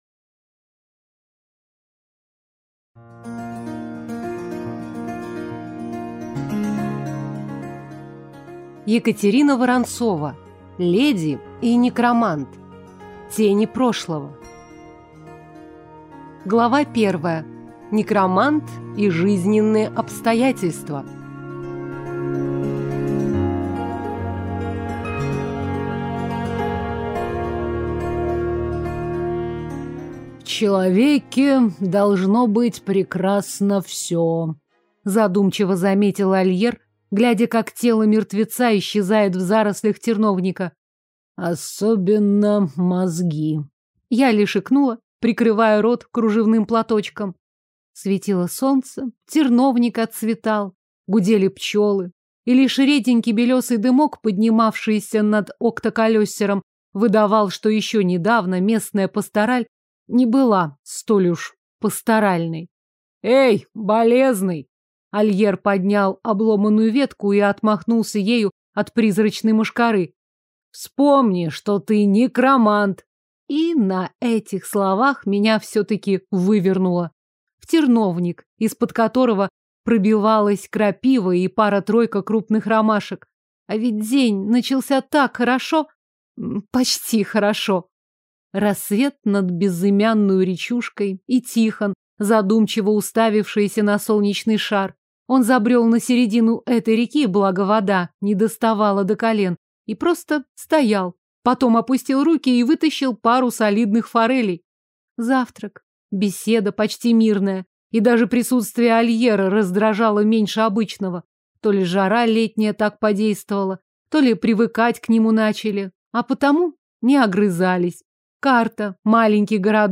Аудиокнига Леди и Некромант. Тени прошлого | Библиотека аудиокниг